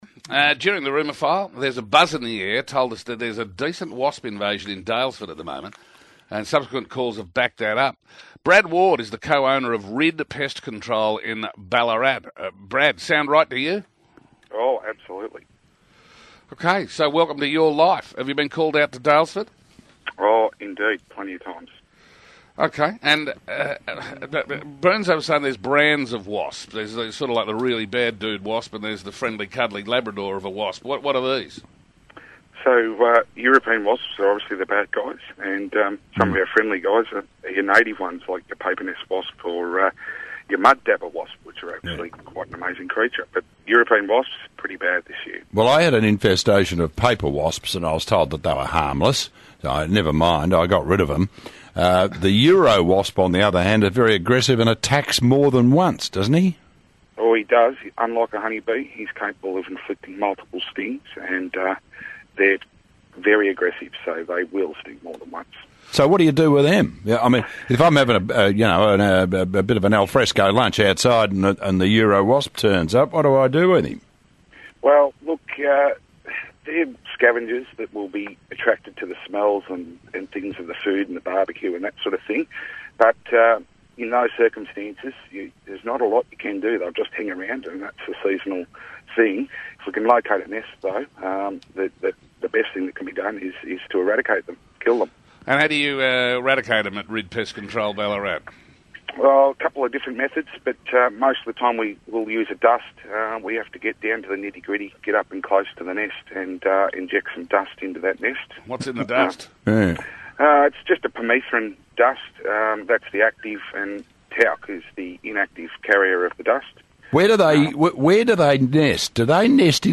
In April 2018 – at the height of the Wasp season we were interviews on Radio 3AW Melbourne by Ross & John as part of the Breakfast Program talking about Wasps!